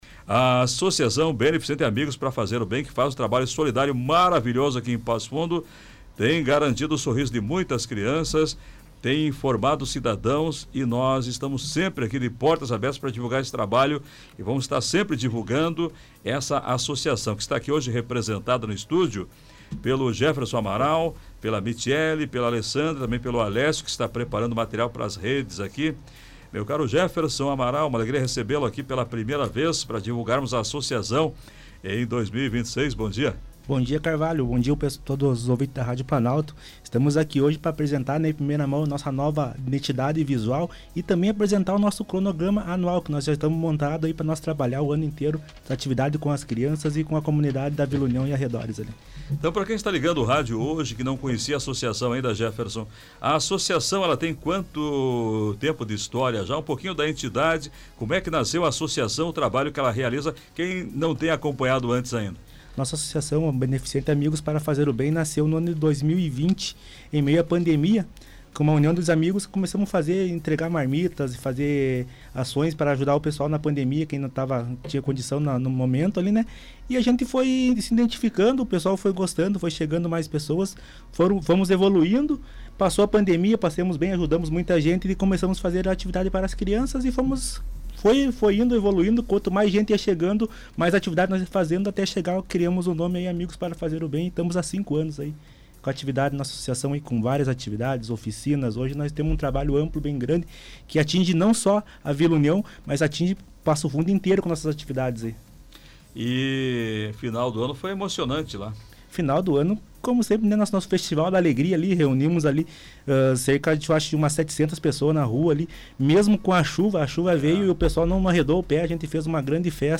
Sua direção visitou a Rádio Planalto News (92.1) para a apresentação do planejamento para o ano. As ações terão início com carnaval infantil, em parceria com o clube Castanha da Rocha.